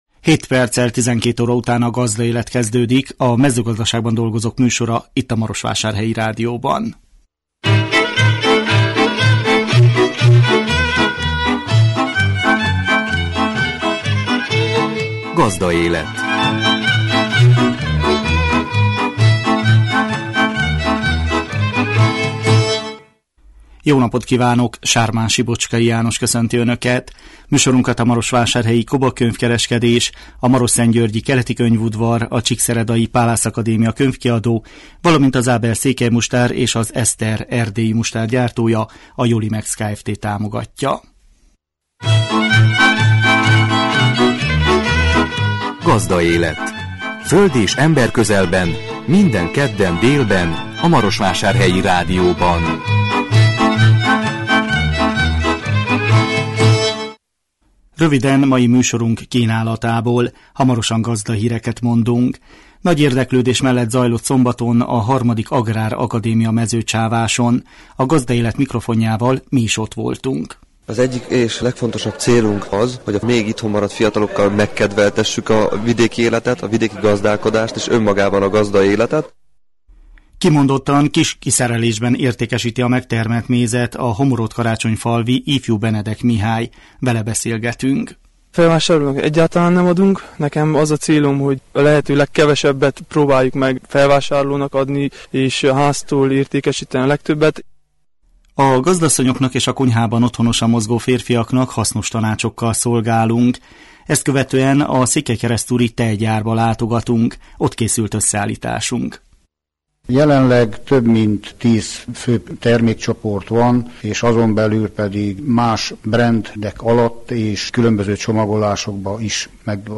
A Gazdaélet mikrofonjával mi is ott voltunk.
Ezt követően a székelykeresztúri tejgyárba látogatunk. Ott készült összeállításunk.